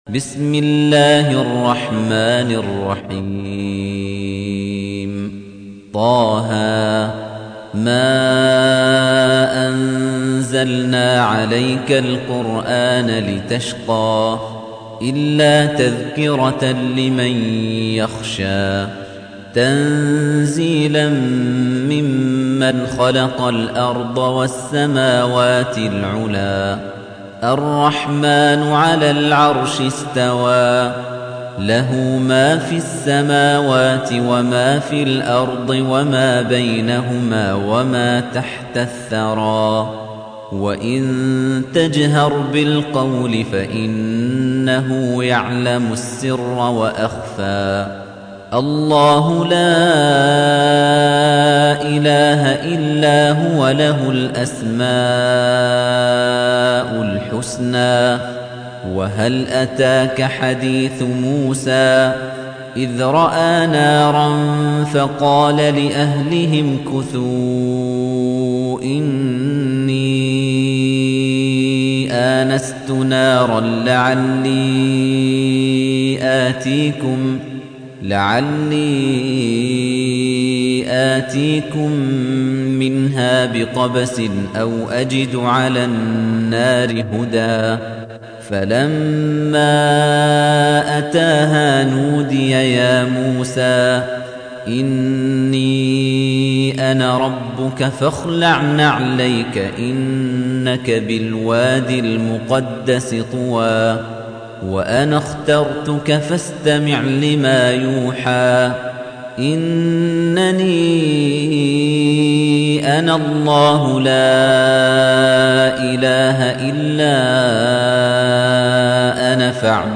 تحميل : 20. سورة طه / القارئ خليفة الطنيجي / القرآن الكريم / موقع يا حسين